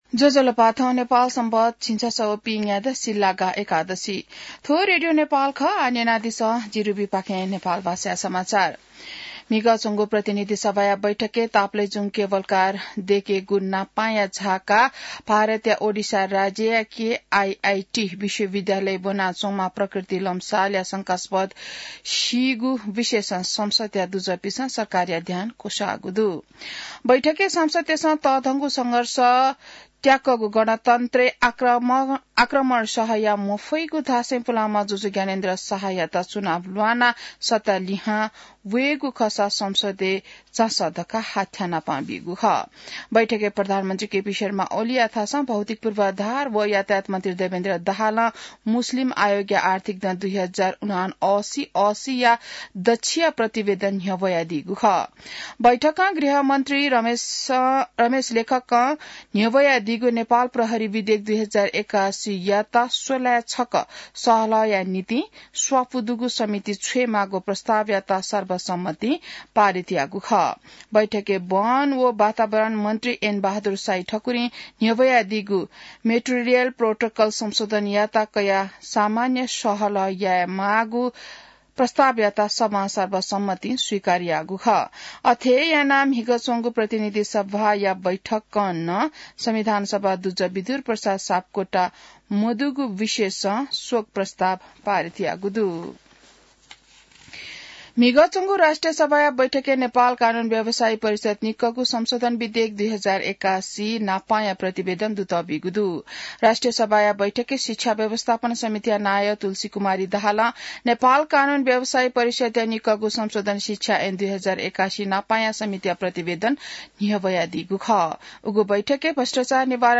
नेपाल भाषामा समाचार : १३ फागुन , २०८१